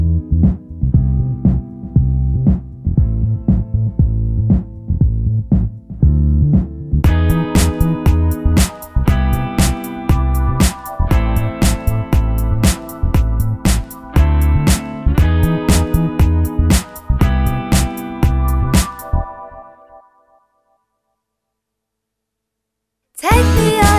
no rap or Backing Vocals R'n'B / Hip Hop 4:10 Buy £1.50